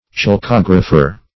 Search Result for " chalcographer" : The Collaborative International Dictionary of English v.0.48: Chalcographer \Chal*cog"ra*pher\, Chalcographist \Chal*cog"ra*phist\, n. An engraver on copper or brass; hence, an engraver of copper plates for printing upon paper.